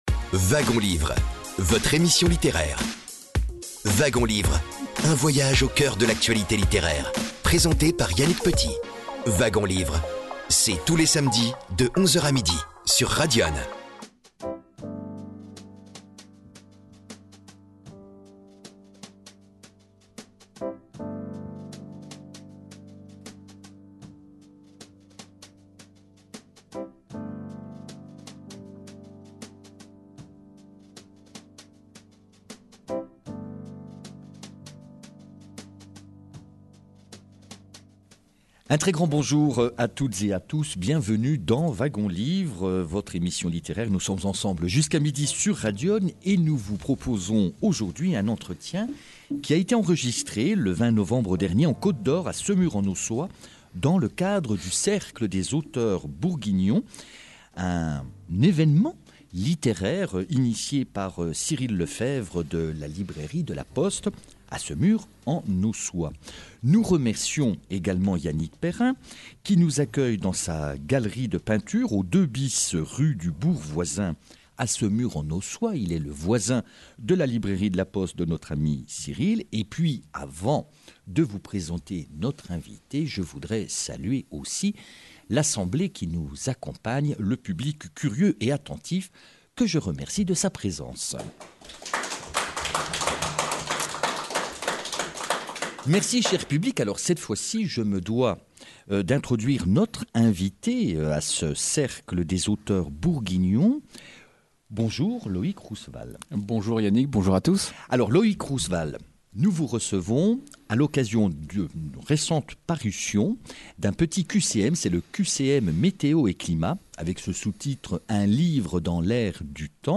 Entretien
Une émission enregistrée en public le 20 novembre 2025